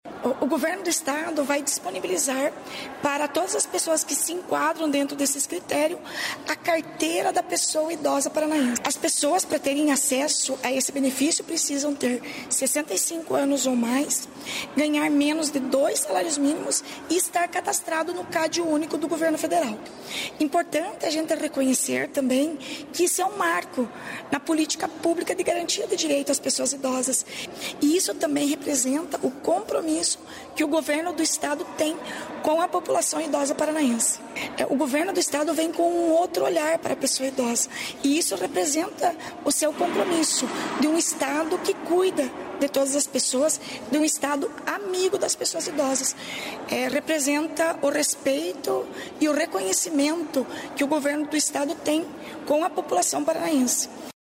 Sonora da secretária da Mulher, Igualdade Racial e Pessoa Idosa, Leandre Dal Ponte, sobre a carteira da Pessoa Idosa